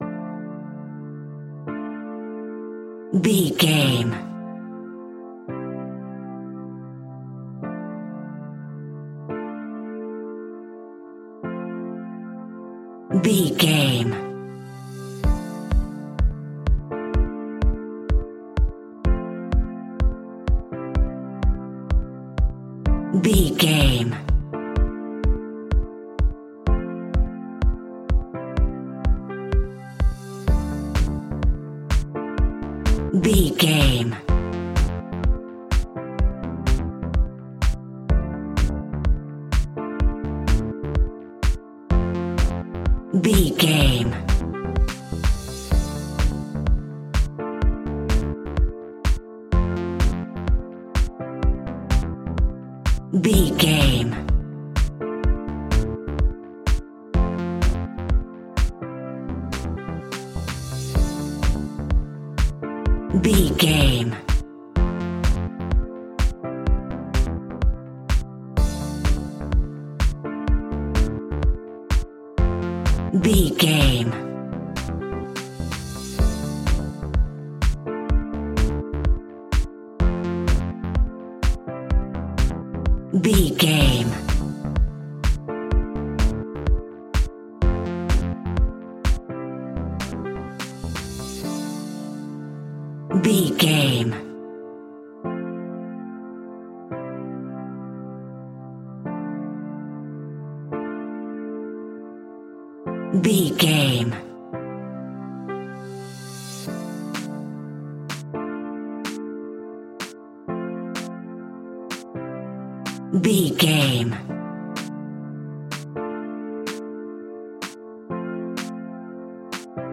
Aeolian/Minor
D
groovy
energetic
uplifting
hypnotic
synthesiser
drum machine
funky house
deep house
nu disco
upbeat
funky guitar
fender rhodes
synth bass